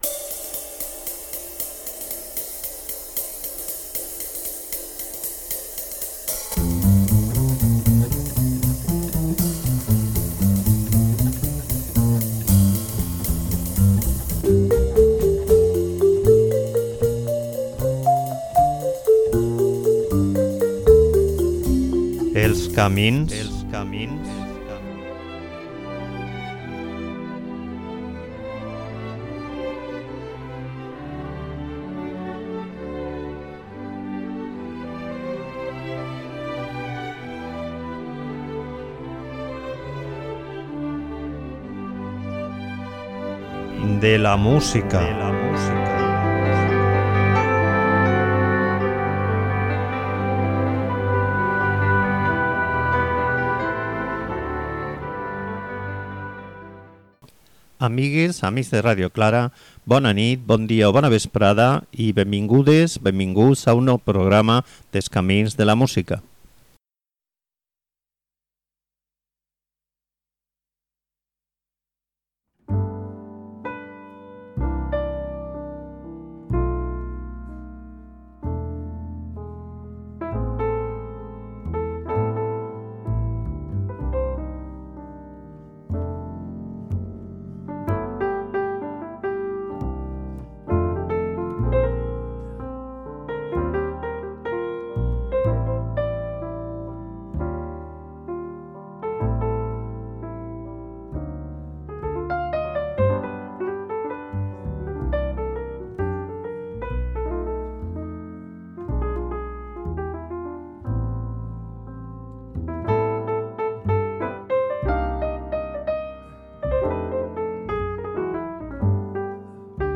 free jazz